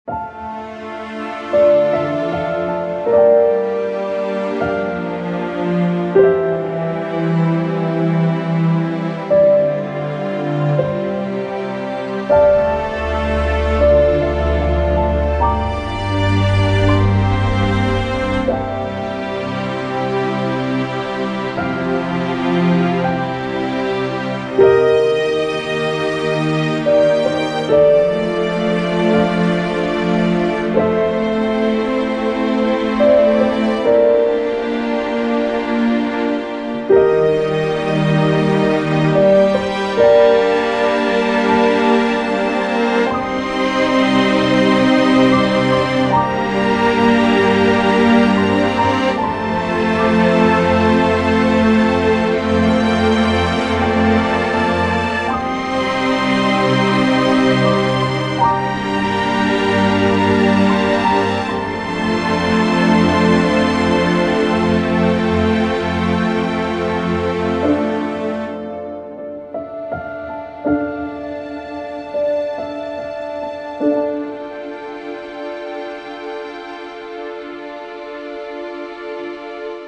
A quiet song inspired by the opening theme of Dark Souls